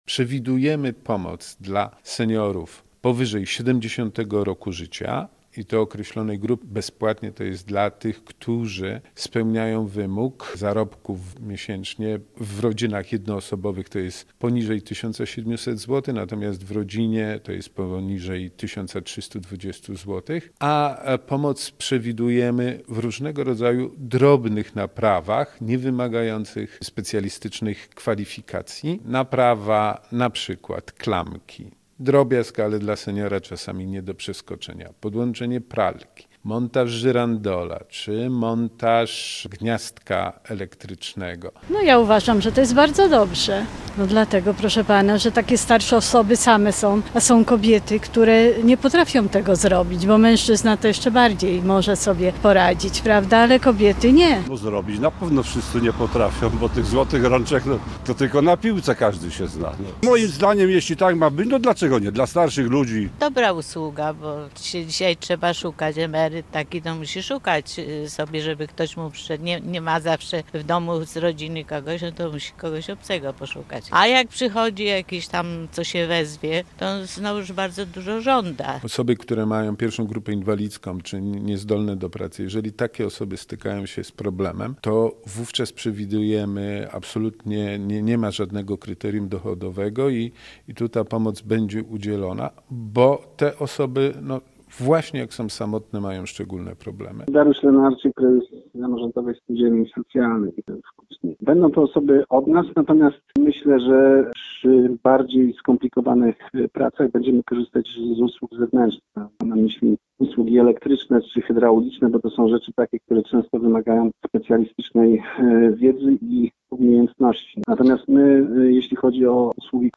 Posłuchaj relacji i dowiedz się więcej: Nazwa Plik Autor W Kutnie będzie działał program “Złota Rączka”.